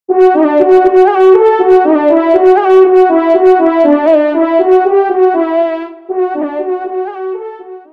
FANFARE
Extrait de l’audio « Ton de Vènerie »